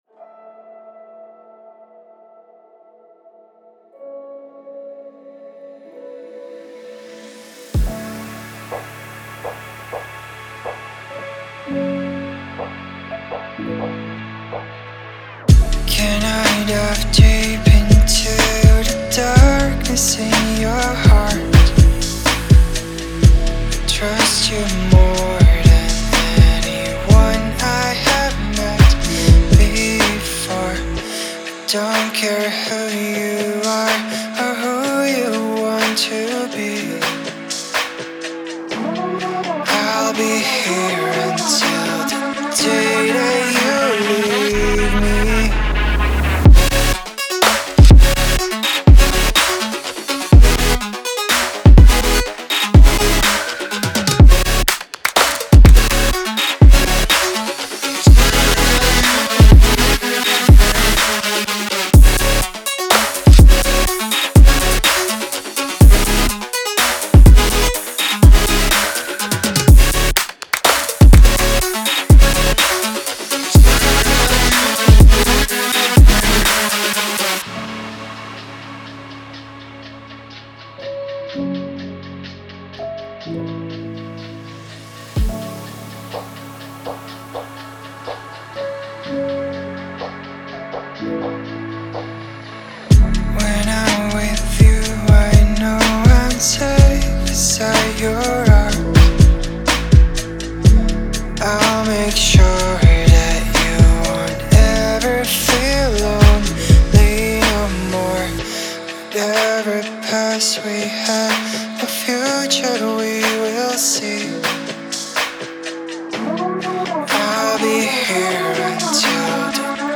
Trap, Relaxing, Quirky, Gloomy, Mysterious